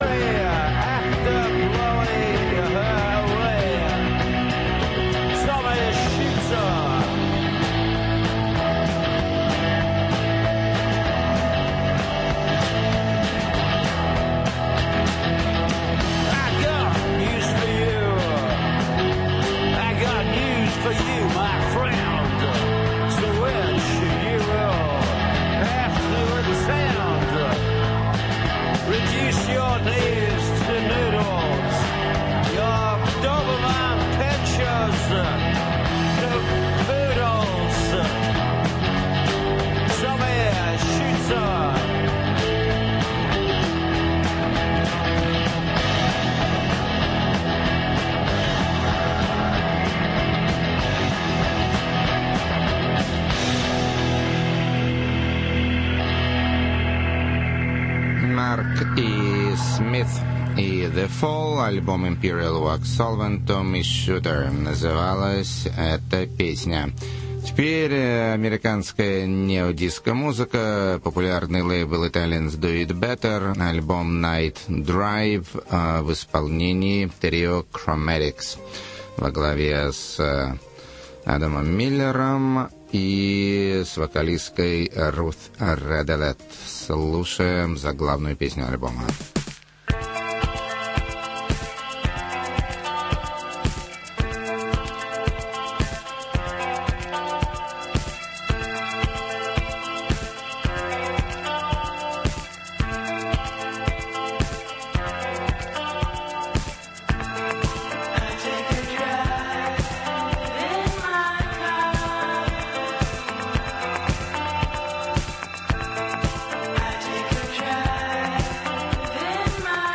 С сожалению, передача началась слишком рано и начало не записалось.